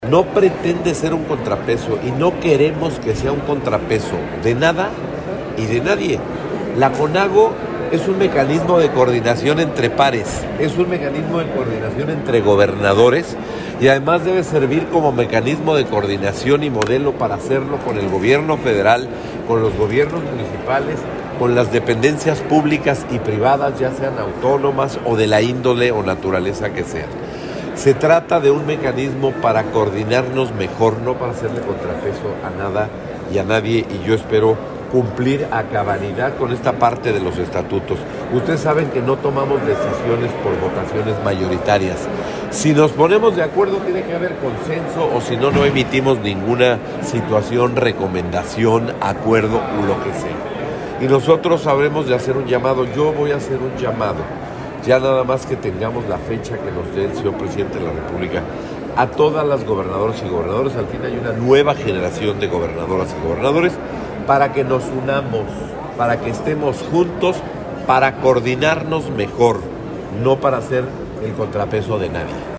Mérida, Yucatán, 16 de noviembre del 2021.- En el marco del Tianguis Turístico 2021 en Mérida, Yucatán, el gobernador de Hidalgo Omar Fayad hizo su primera intervención como presidente de la Conferencia Nacional de Gobernadores (Conago) en la reunión de la Comisión de Turismo.